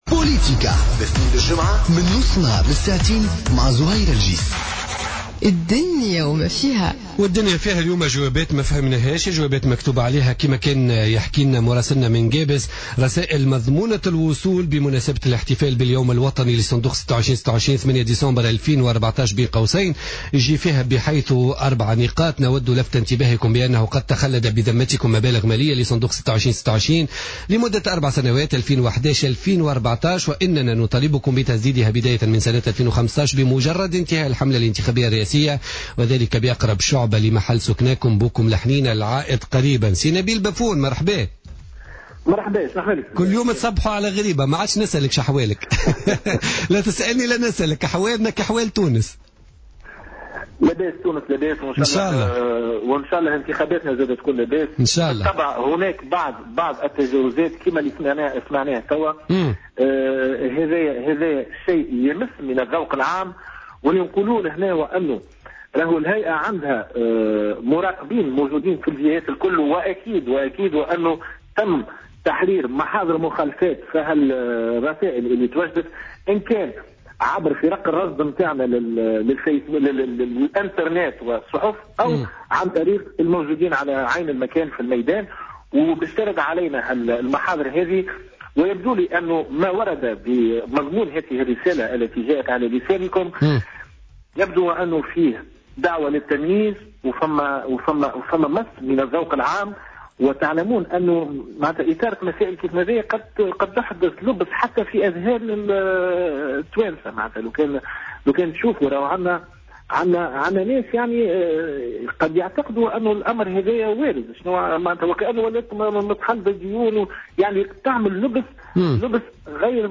أكد عضو الهيئة العليا المستقلة للانتخابات نبيل بفون في مداخلة له في برنامج بوليتيكا اليوم الجمعة 12 ديسمبر 2014 ان ظاهرة توزيع رسائل ممضاة من قبل بن علي والتي شهدتها عدة مناطق تونسية على غرار قابس وتطالب المواطنين بدفع ما تخلد بذمتهم لفائدة صندوق 26 /26 تعد مخالفة انتخابية واضحة.